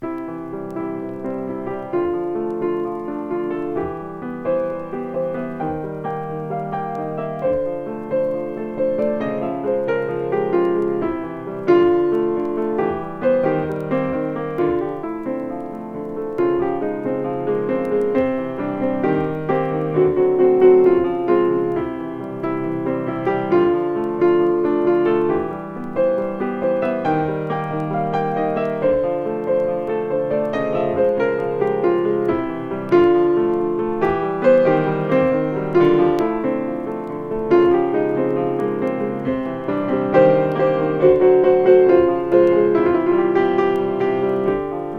スリリングと閃き、エモーショナルな瞬間も訪れる怒涛な演奏が最高です。